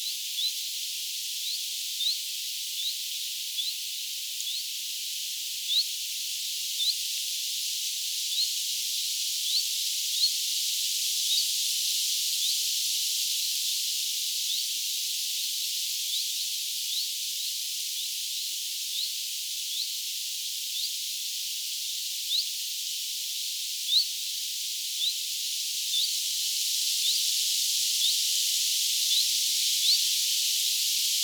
Viisi vit-tiltalttia kuului tänään.
vit-tiltaltti